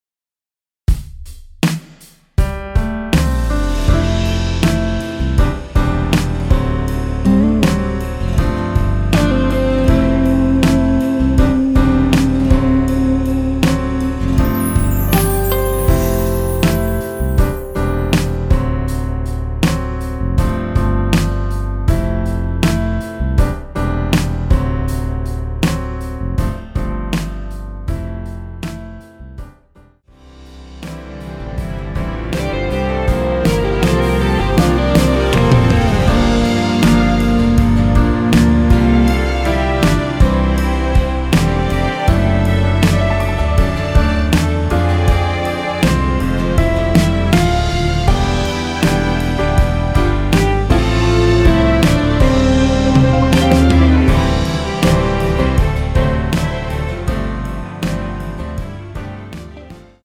엔딩이 페이드 아웃이라 라이브 하시기 좋게 엔딩을 만들어 놓았습니다.
원키에서 (-4)내린 2절 삭제? MR 입니다.(미리듣기및 아래의 가사 참조)
◈ 곡명 옆 (-1)은 반음 내림, (+1)은 반음 올림 입니다.
앞부분30초, 뒷부분30초씩 편집해서 올려 드리고 있습니다.